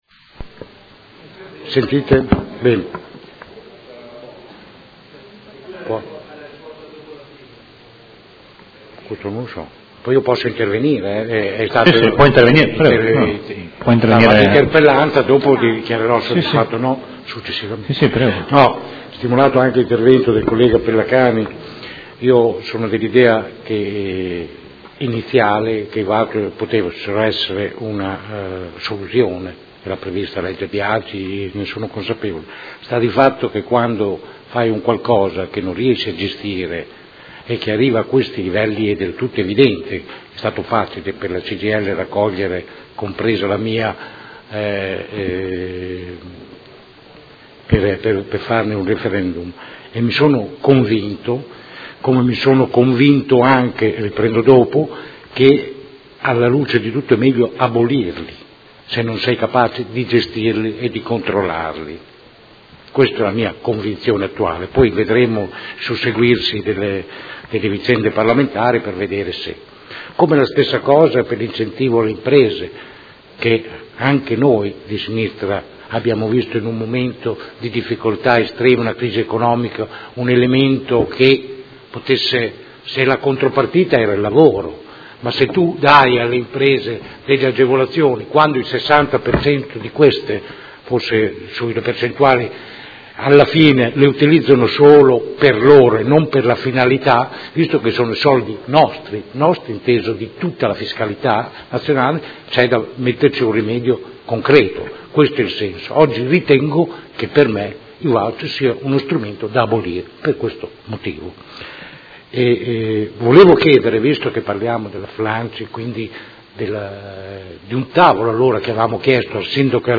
Seduta del 19/01/2017 Interrogazione dei Consiglieri Carpentieri e Baracchi (P.D.) avente per oggetto: Ristorante Flunch presso il Grandemilia: sostituzione dei lavoratori in sciopero utilizzando i così detti “voucher” Interrogazione del Consigliere Rocco (FAS-SI), dal Consigliere Cugusi (SEL) e dai Consiglieri Campana e Chincarini (Per Me Modena) avente per oggetto: Utilizzo dei Voucher per pagare lavoratori in sostituzione di altri lavoratori in sciopero. Dibattito